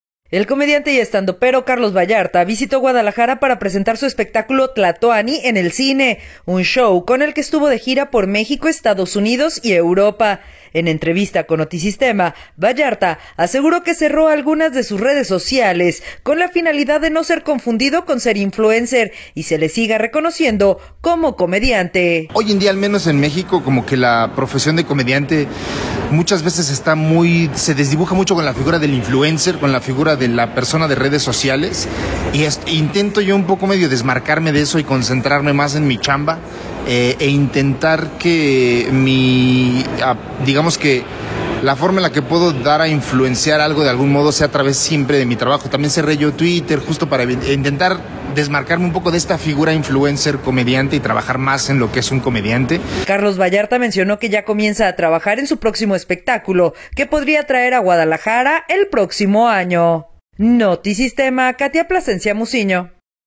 En entrevista con Notisistema, Ballarta aseguró que cerró algunas de sus redes sociales, con la finalidad de no ser confundido con ser un influencer y se le siga reconociendo como comediante.